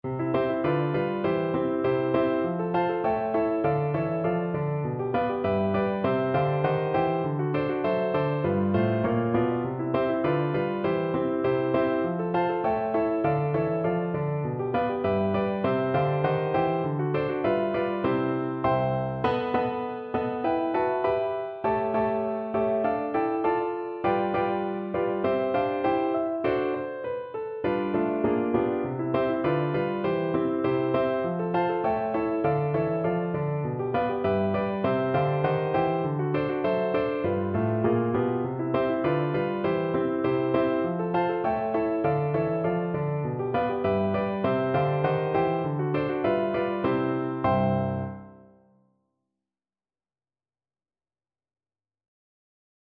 No parts available for this pieces as it is for solo piano.
2/2 (View more 2/2 Music)
Piano  (View more Intermediate Piano Music)
Classical (View more Classical Piano Music)